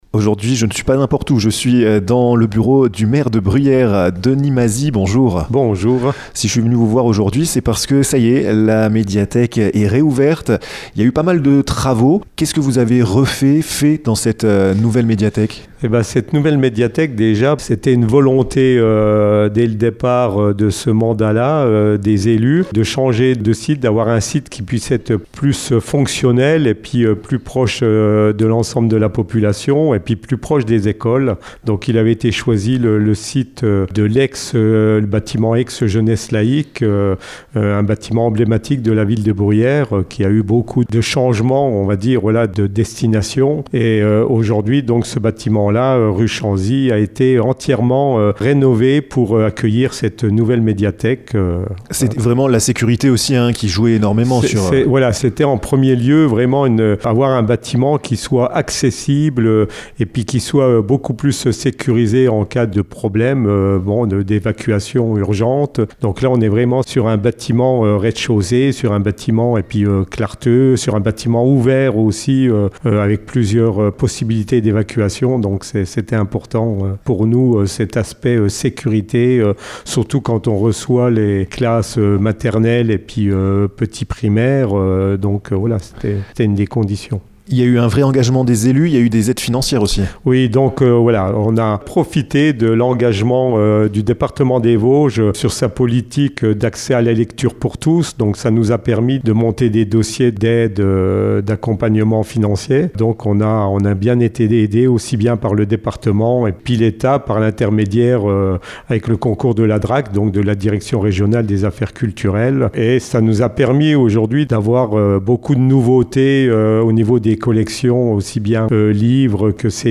Denis Masy, le maire de Bruyères, répond à toutes ces questions dans ce podcast!